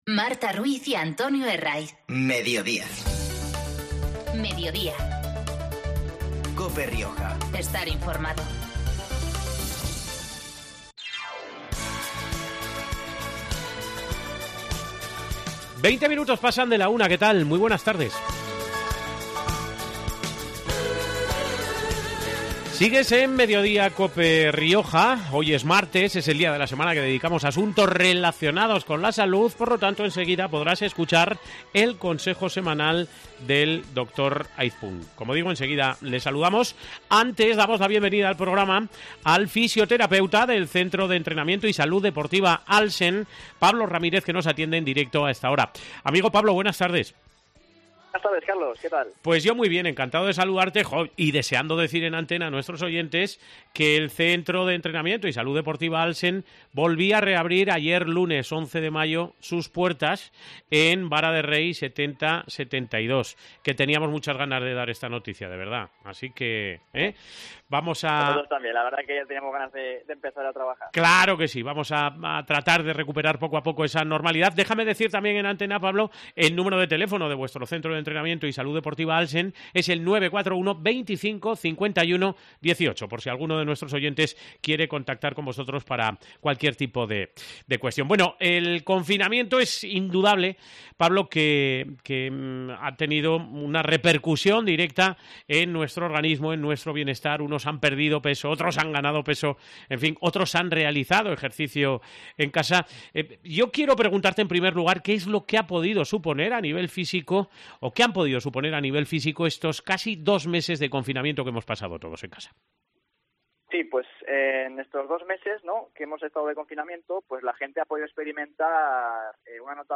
Este martes ha pasado por los micrófonos de COPE Rioja para analizar las consecuencias del confinamiento.